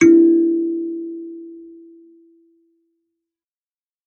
kalimba2_wood-E3-ff.wav